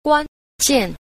7. 關鍵 – guānjiàn – quan kiện (then chốt)
guan_jian.mp3